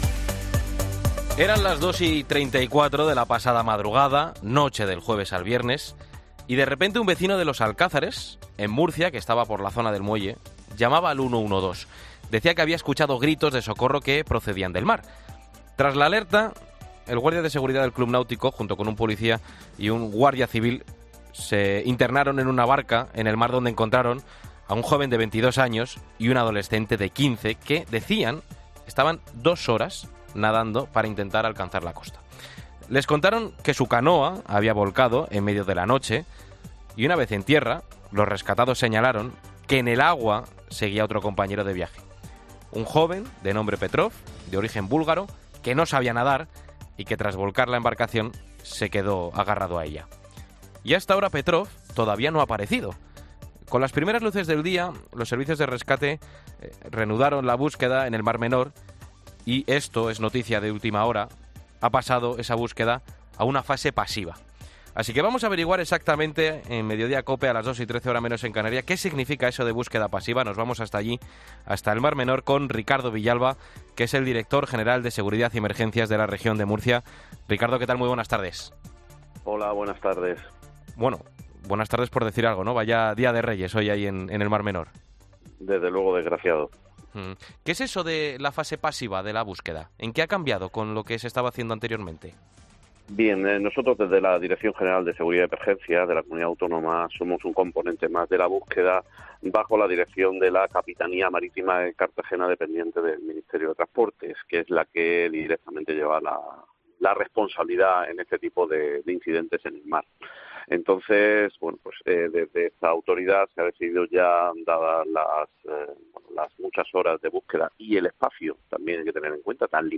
Respondemos a estas preguntas en 'Mediodía COPE' con Ricardo Villalba, el director general de Seguridad y Emergencias de la Región de Murcia.